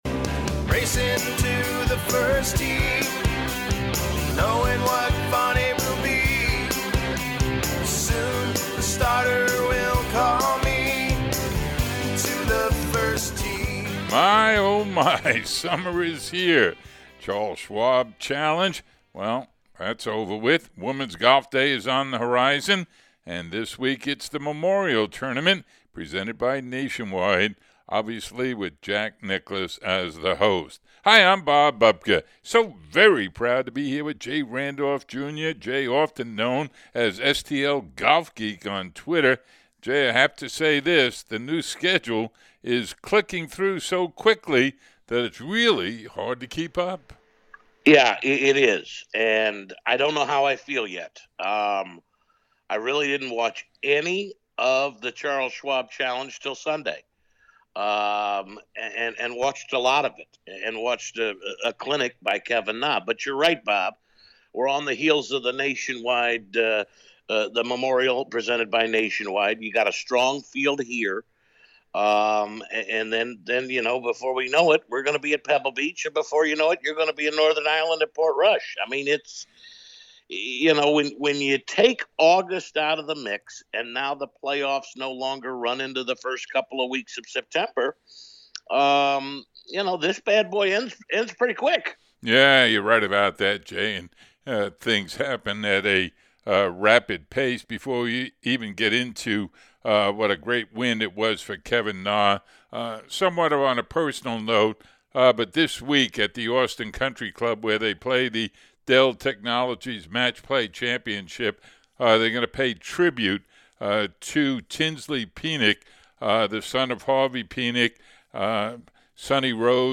Also on the menu: a look at The Memorial and some US Open preview talk. Feature Interview